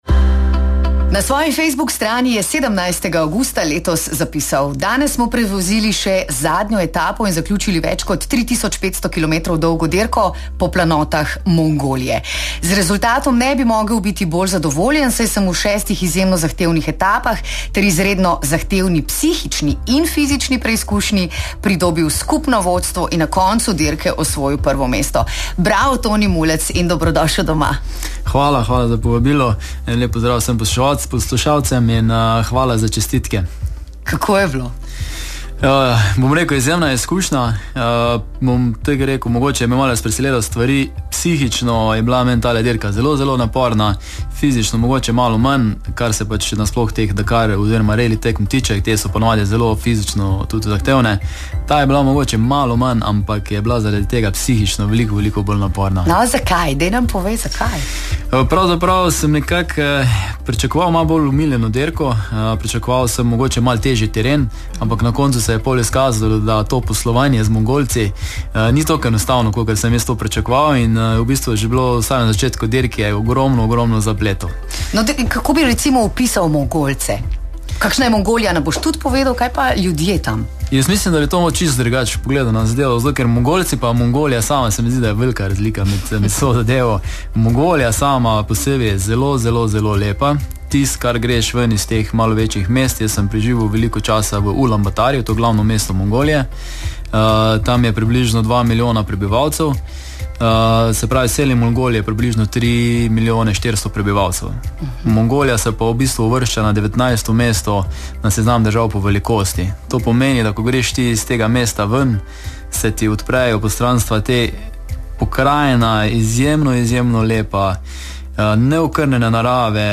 obiskal v studiu